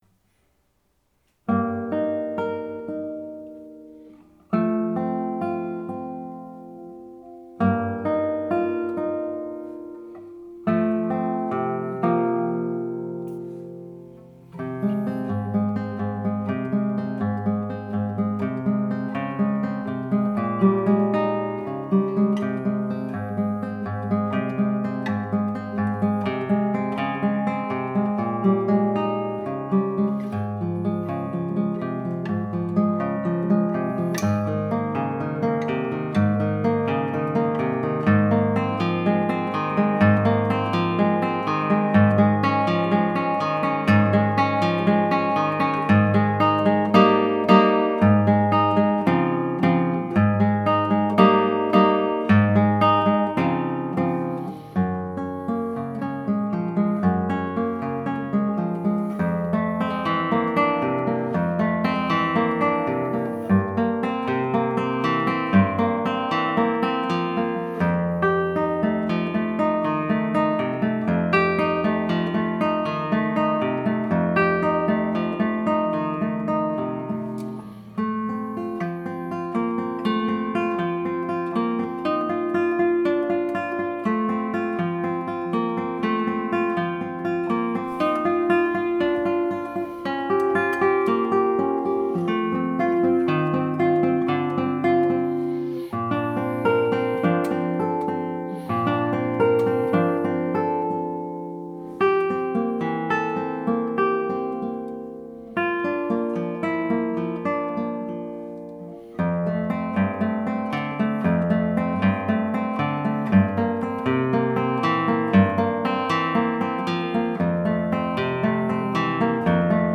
ALT-CLASSICAL